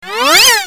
cartoon42.mp3